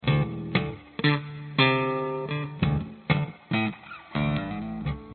时髦的Bass Riff 2
描述：用电贝司（Fender Preci）弹奏时髦的拍击乐。
Tag: 贝斯 芬德 吉他 精确